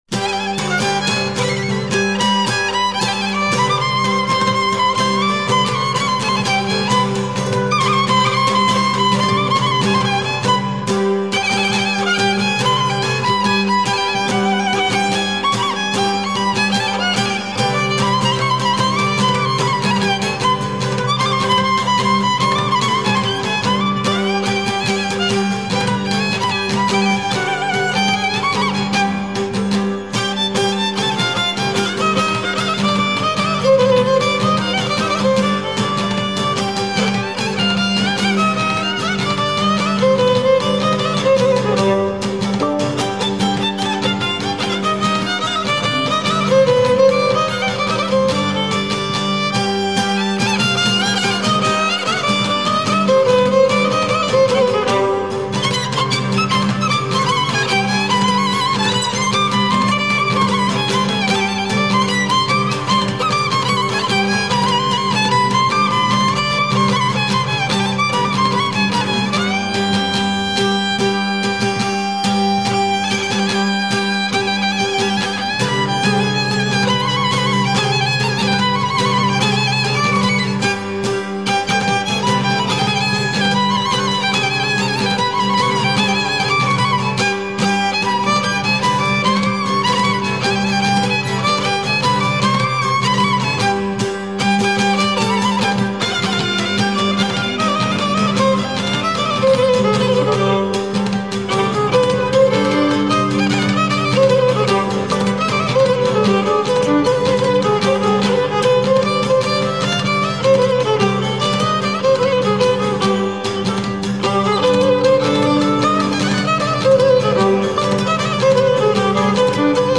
ΤΡΑΓΟΥΔΙΑ ΚΑΙ ΣΚΟΠΟΙ ΑΠΟ ΤΑ ΔΩΔΕΚΑΝΗΣΑ